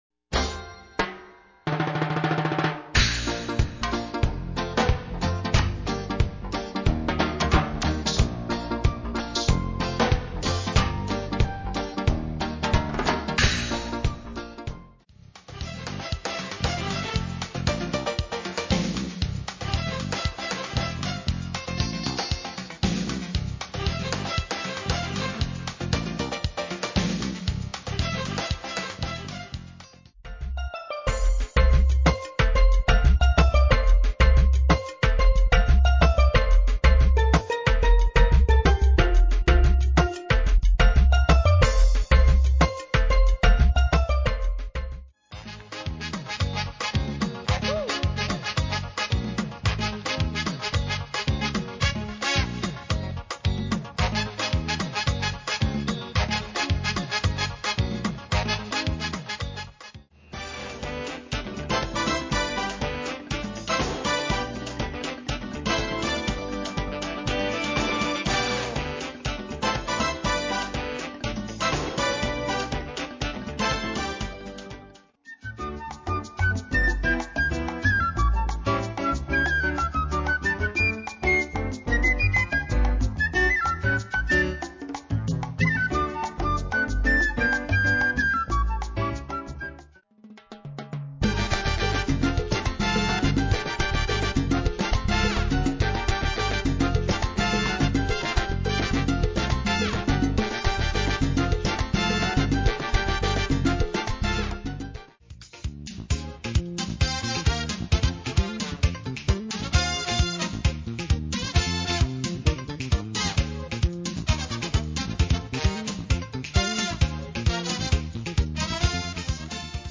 Latin Dance